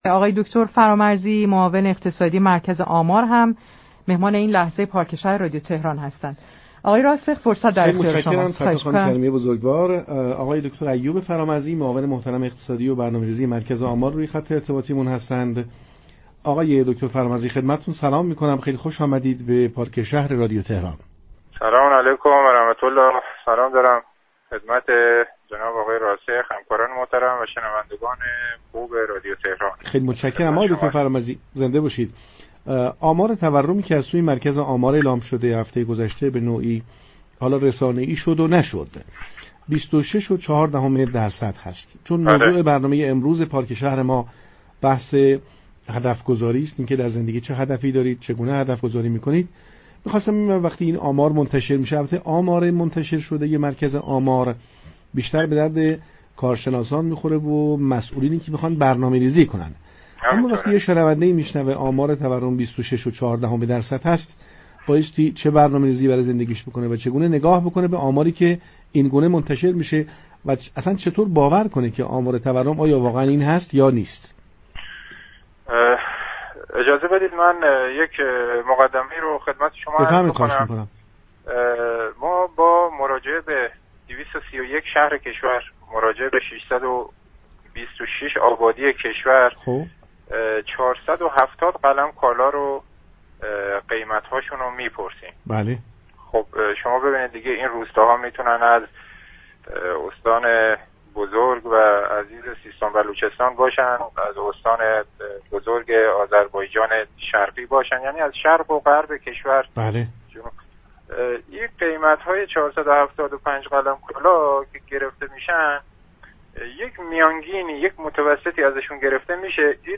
دكتر ایوب فرامرزی، معاون اقتصادی و برنامه ریزی مركز آمار در گفتگو با پارك شهر رادیو تهران گفت: اگر روند تورم ماهانه با همین سرعت پیش برود تا پایان سال حداقل 40 درصد تورم خواهیم داشت.